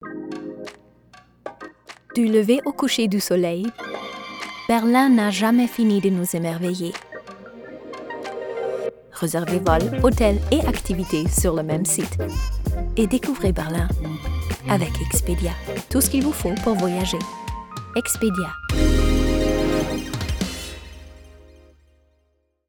dunkel, sonor, souverän, markant, sehr variabel, plakativ, hell, fein, zart
Tutorial